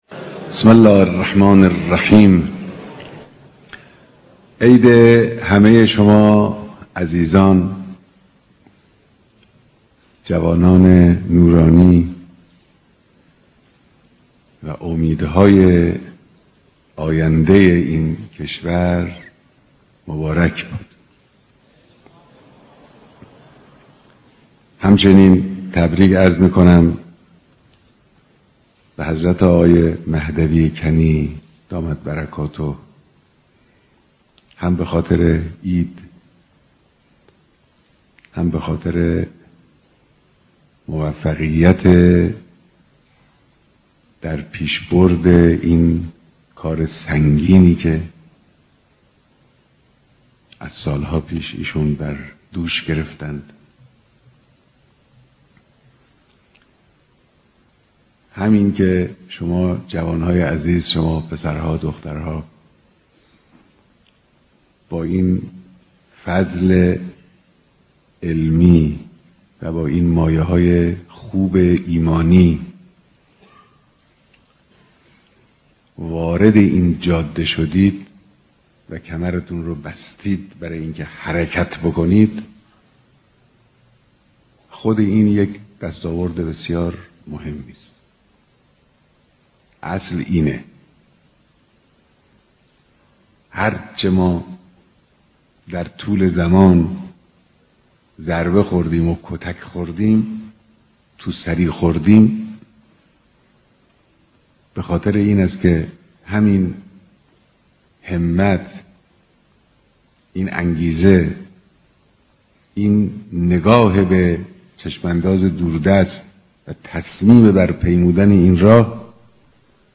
ديدار مسؤولان، استادان و دانشجويان دانشگاه امام جعفر صادق(ع)